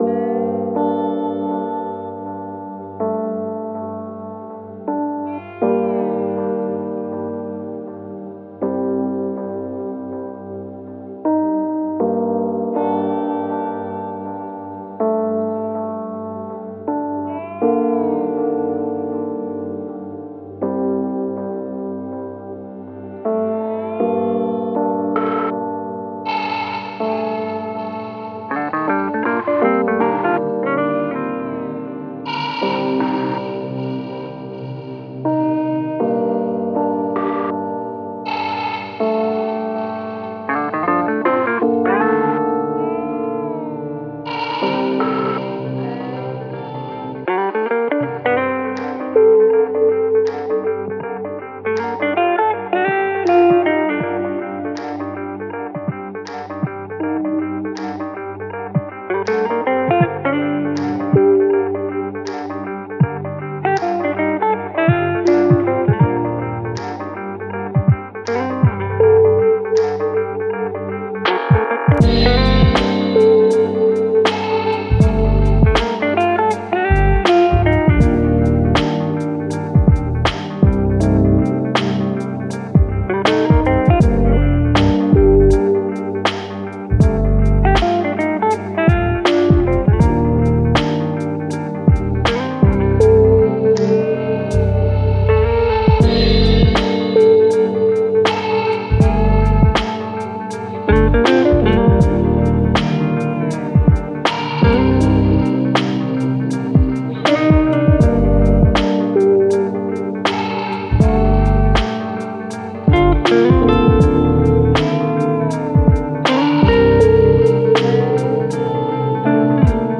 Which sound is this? Genre: chillhop, lofi, triphop.